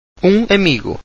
oom[ng] ameegu – a male friend